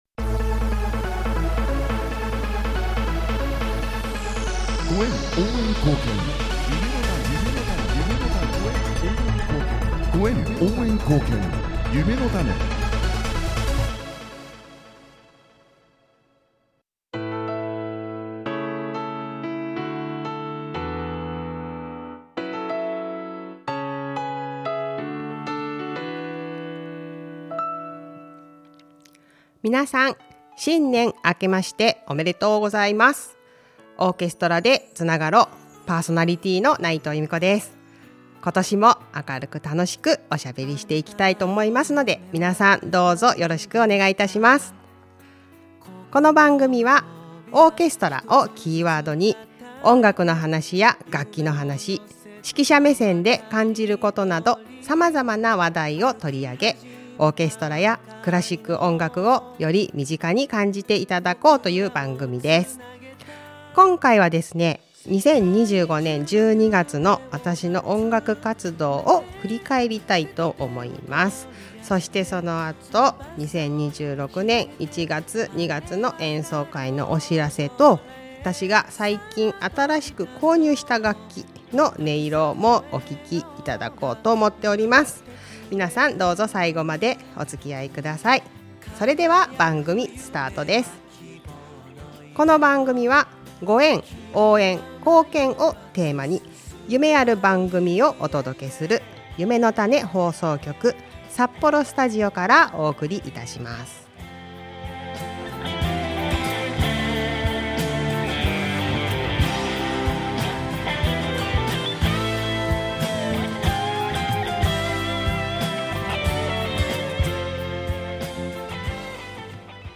柔らかくて、包み込まれるような音色、どうぞお聞きくださいませ😊